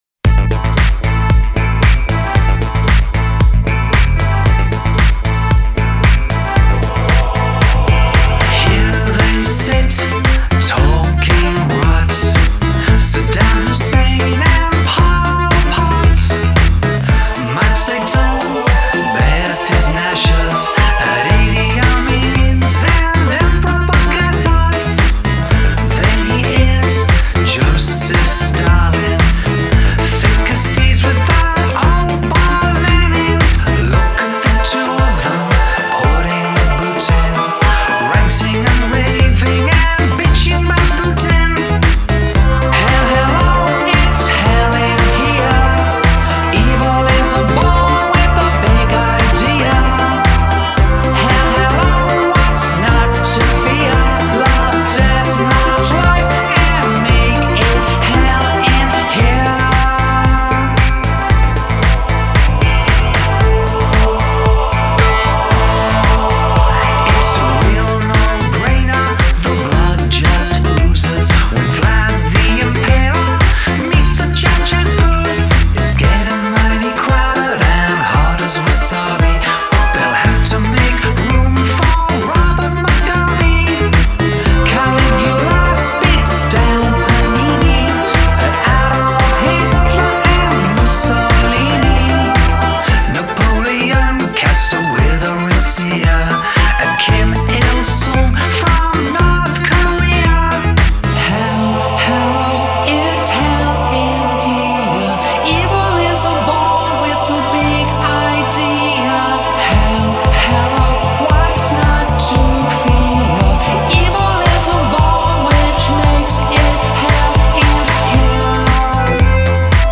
Жанр: Pop; Битрэйт